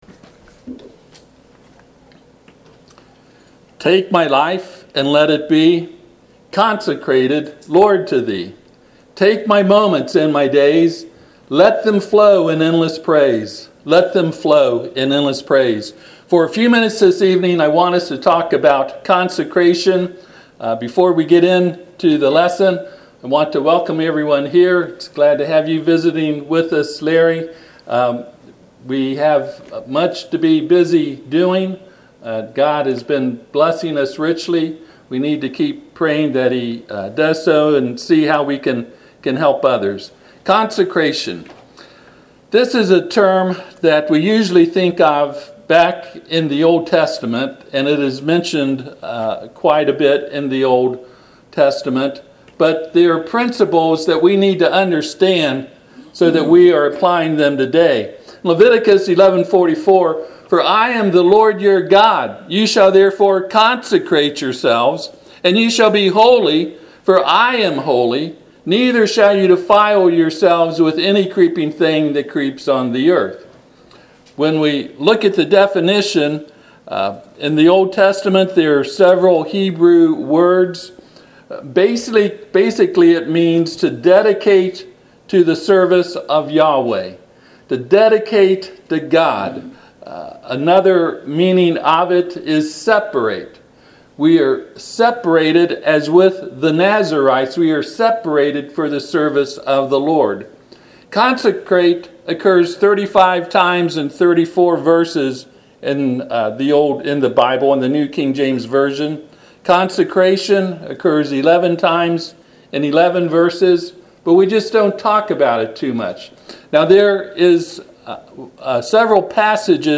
Passage: Leviticus 11:44 Service Type: Sunday PM https